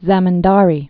(zămən-därē, zĕm-, zə-mēn-)